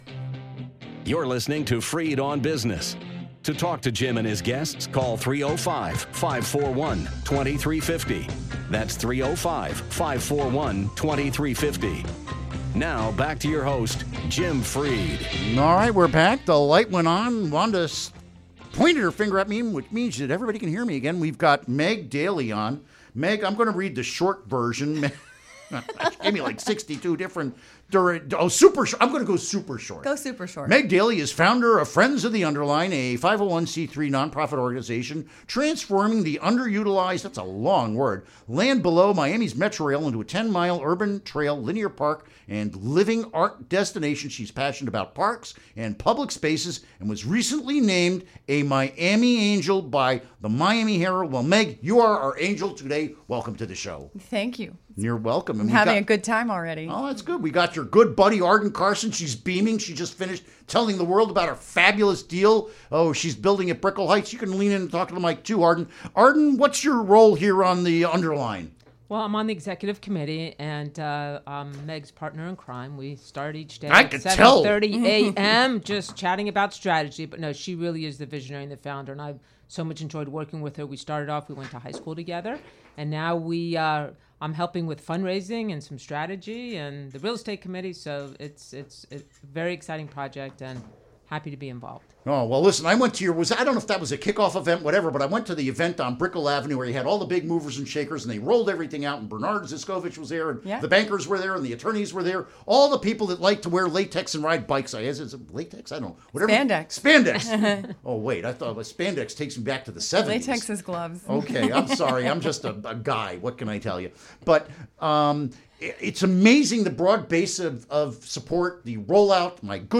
Interview Segment Episode 373: 06-16-16 Click here to download Part 1 (To download, right-click and select “Save Link As”.)